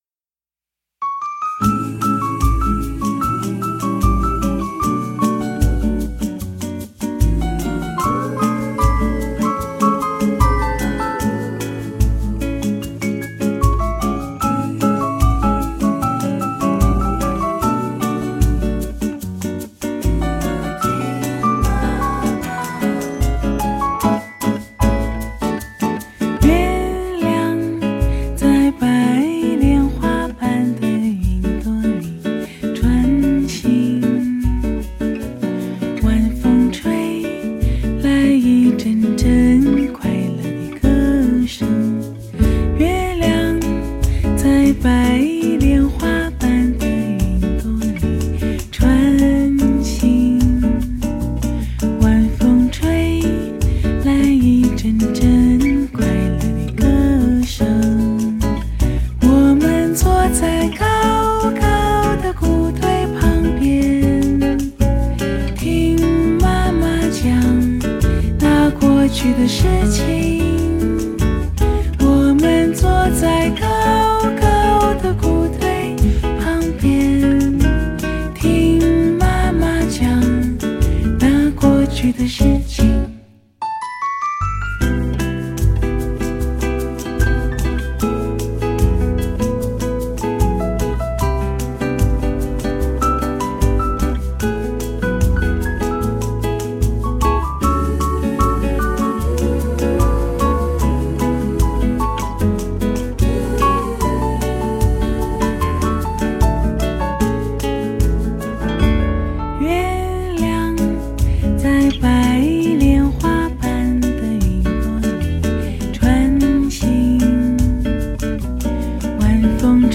将国人耳熟能详的流行经典歌曲，全部以Bossa Nova音乐进行改编，
最纯正配乐和打击乐
慵懒甜美 浪漫性感 慢调生活音乐SPA
一种是象征自由、放松、浪漫的南美Bossa Nova，一种是代表岁月经典、传唱至今的中国流行音乐。